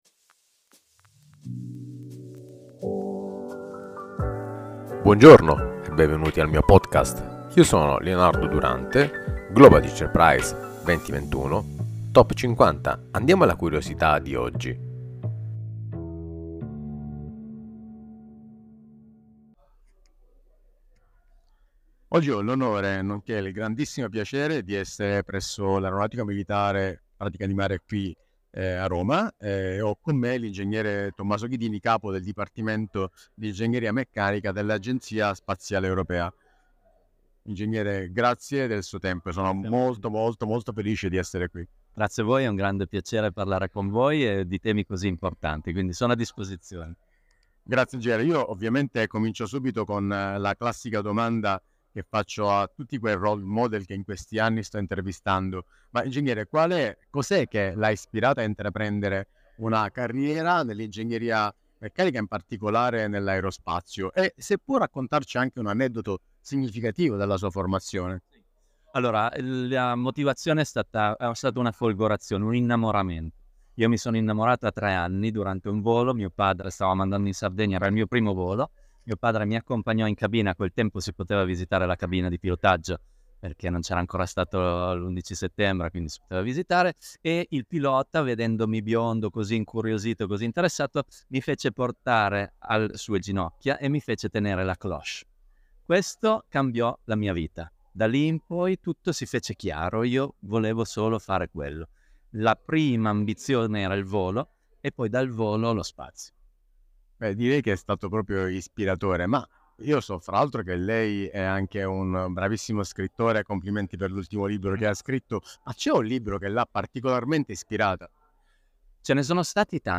19/02/2025 Intervista all'Ing.